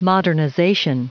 Prononciation du mot modernization en anglais (fichier audio)
Prononciation du mot : modernization
modernization.wav